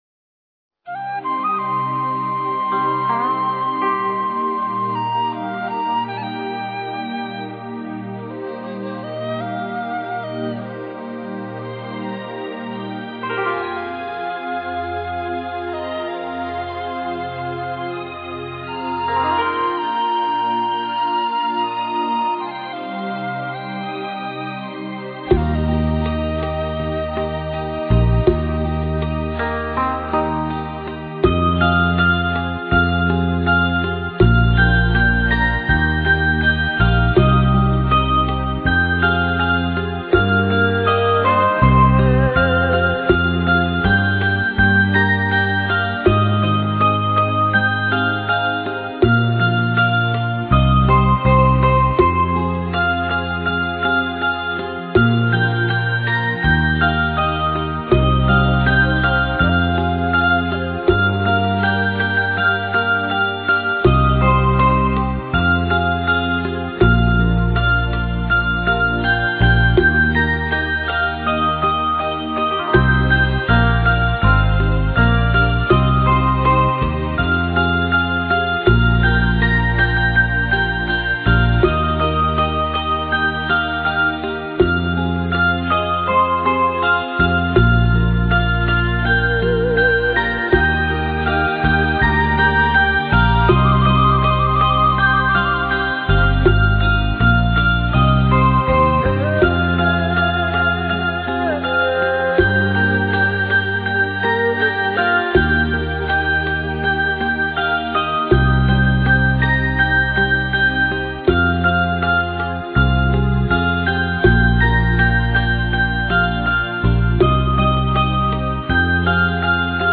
纯音乐-大悲咒--水晶佛乐 - 冥想 - 云佛论坛
纯音乐-大悲咒--水晶佛乐